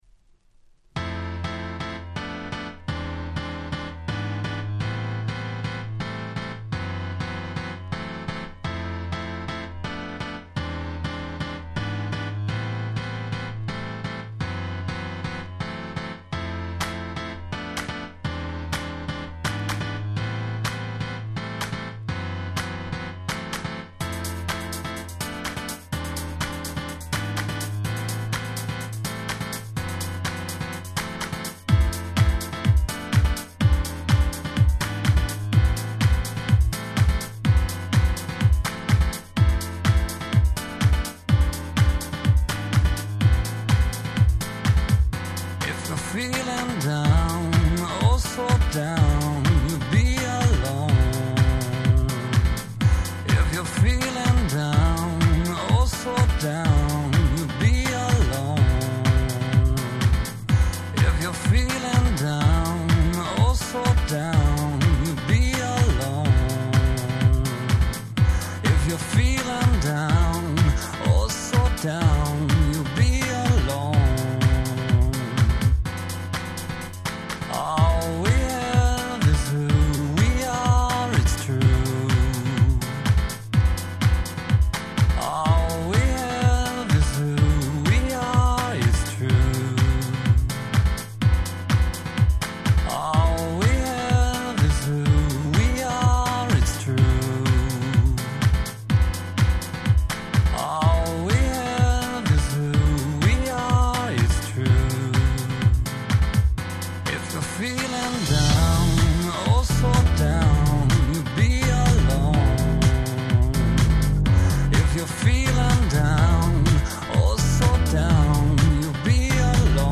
所謂「乙女系House」の最高峰。